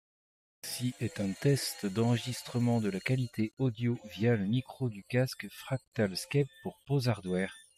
Trong môi trường ồn ào (nhạc lớn), giọng nói được ưu tiên và vẫn có thể nghe rõ. Tiếng ồn xung quanh được cách ly để ưu tiên giọng nói.
• [Mẫu âm thanh Micro rời và nhạc lớn]